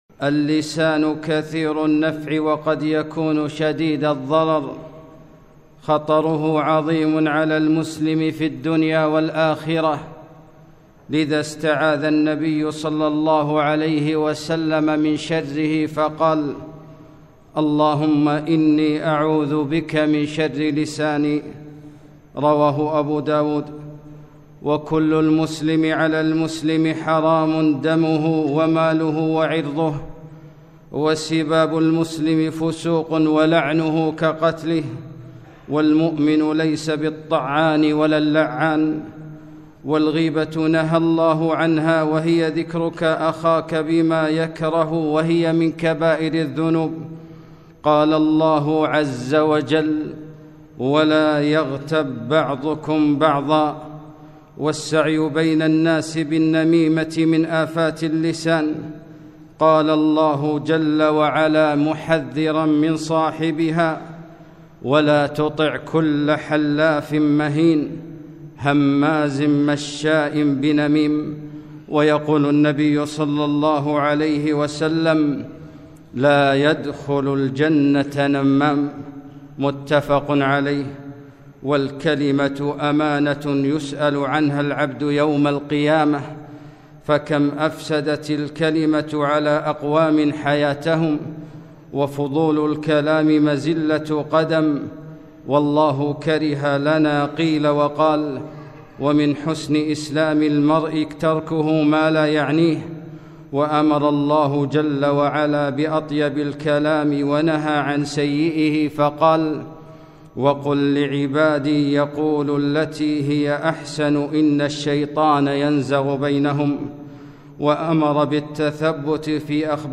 خطبة - أمسك عليك هذا